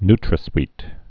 (ntrə-swēt)